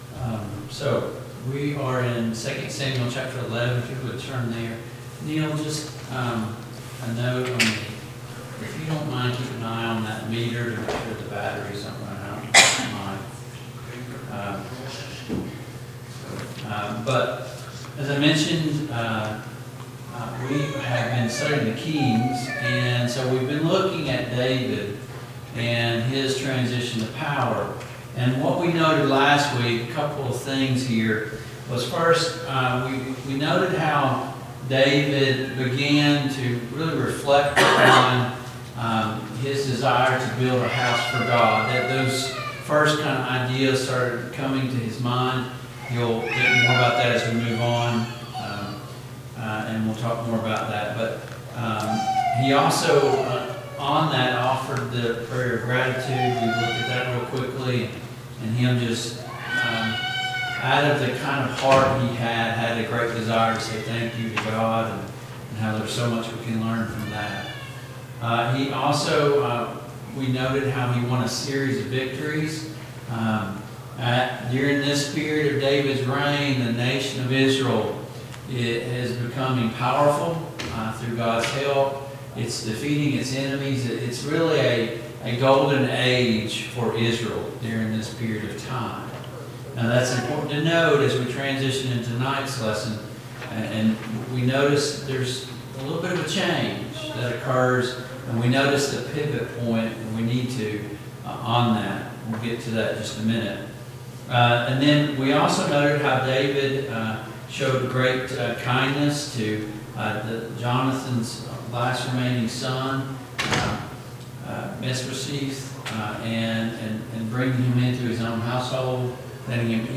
II Samuel 11:1-27 Service Type: Mid-Week Bible Study Download Files Notes Topics: David's sin with Bathsheba « Can I fall from God’s grace?